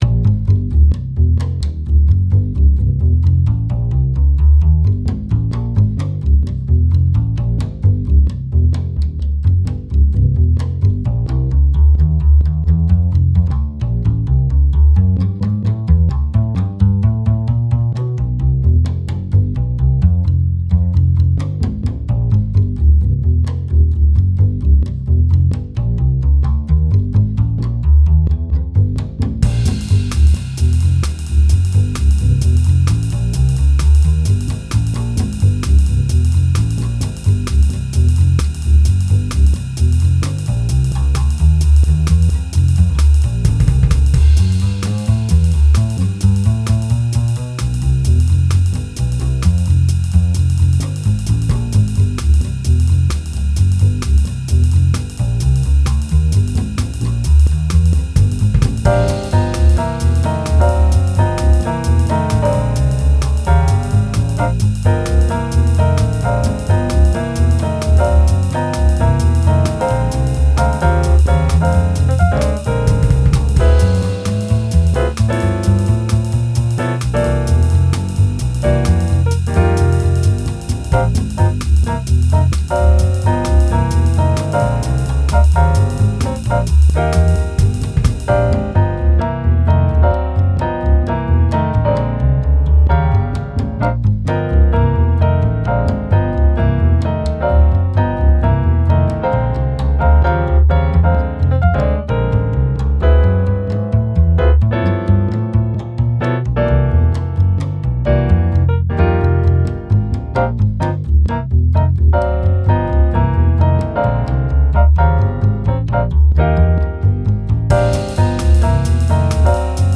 Track 4 “Rhythm Section”